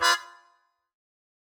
GS_MuteHorn-Fdim.wav